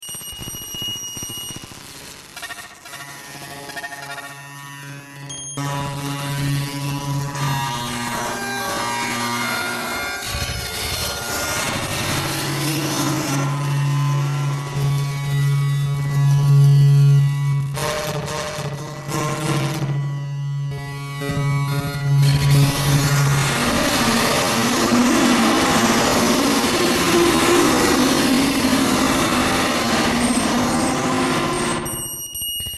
データ処理やコンピューターアクティビティのリアルな音をループ形式です。